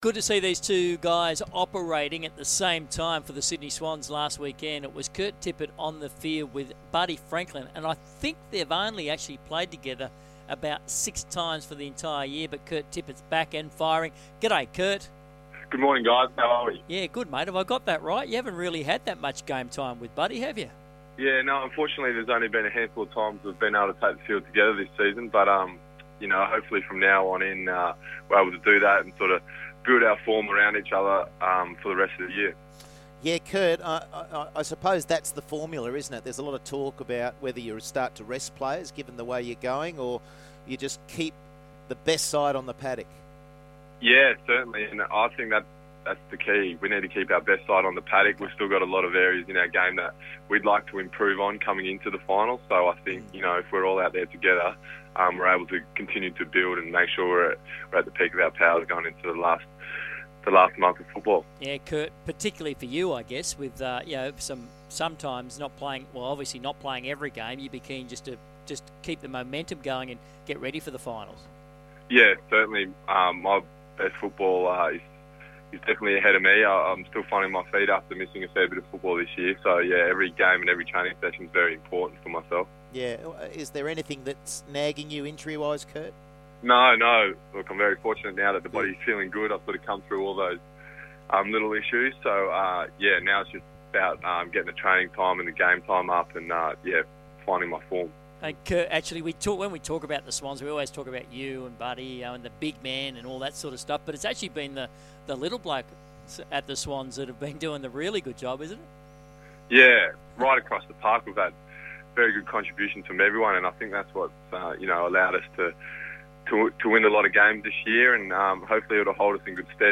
Sydney Swans forward Kurt Tippett spoke to Sky Sports Radio's Big Sports Breakfast program on Tuesday August 12, 2014